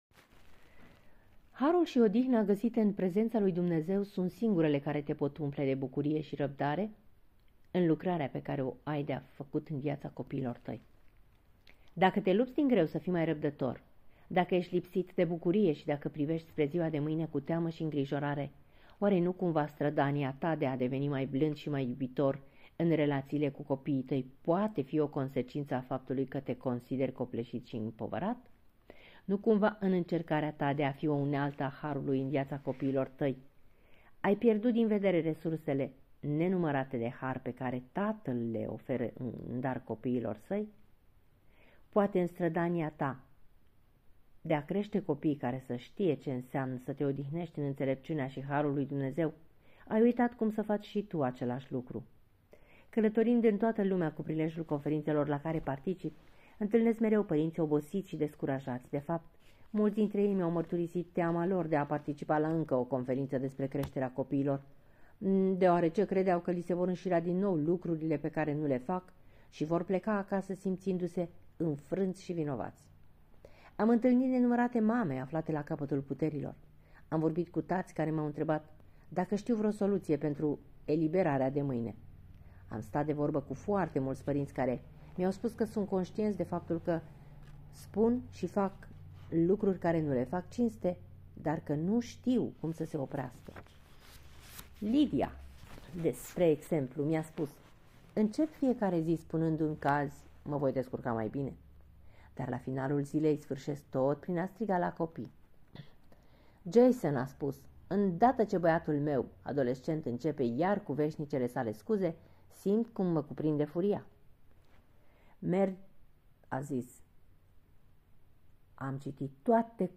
Acest podcast este prima parte a celui de-al treisprezecelea capitol al cărții "Pentru părinți - 14 principii care îți pot schimba radical familia" de la Paul David Tripp.